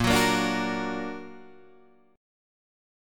A# Augmented